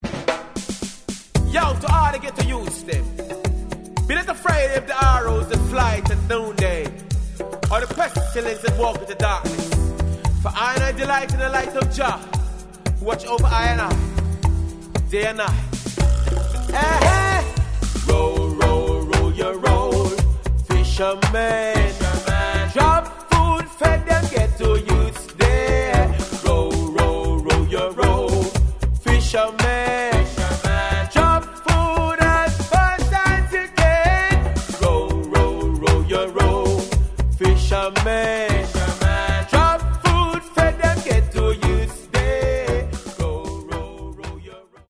Reggae Ska Dancehall Roots Vinyl Schallplatten ...